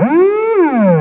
Wingnuts / SFX
1 channel